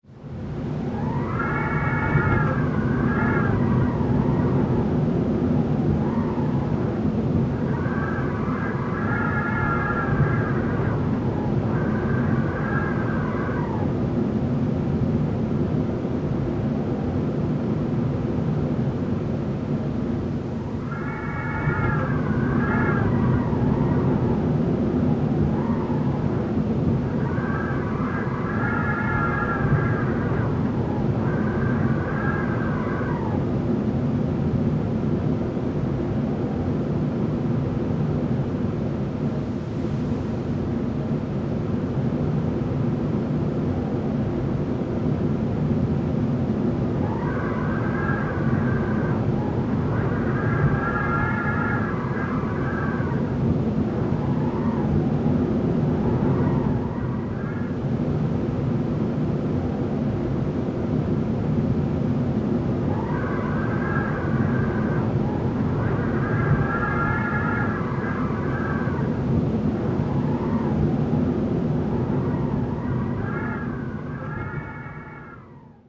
wind.ogg